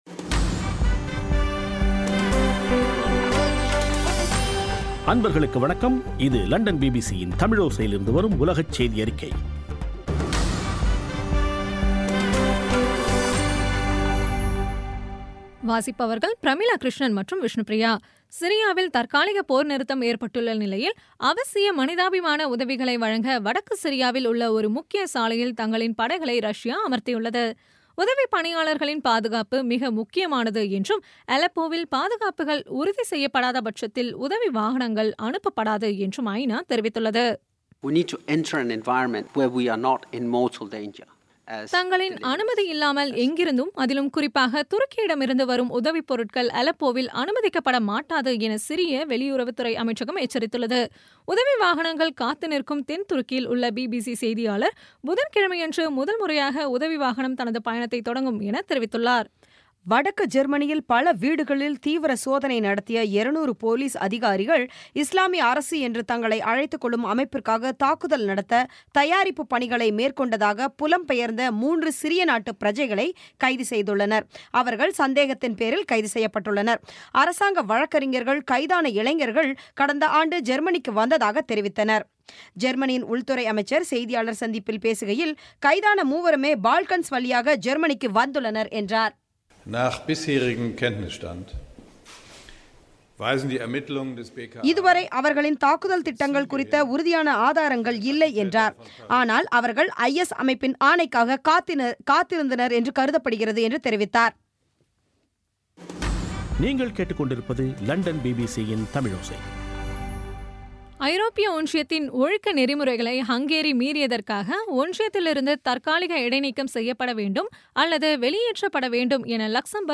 பி பி சி தமிழோசை செய்தியறிக்கை (13/09/2016)